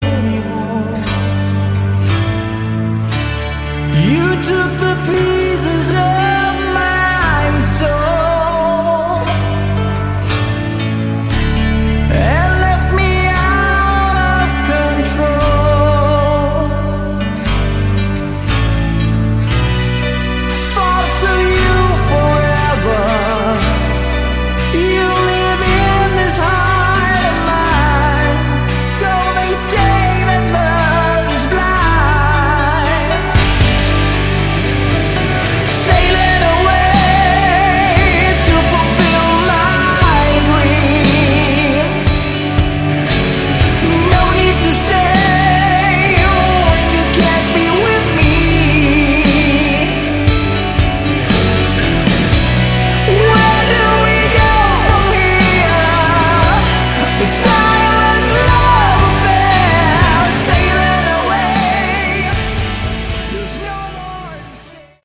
Category: Hard Rock
vocals, backing vocals
guitars, keyboards, backing vocals